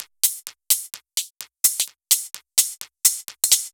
Index of /musicradar/uk-garage-samples/128bpm Lines n Loops/Beats
GA_BeatFiltC128-04.wav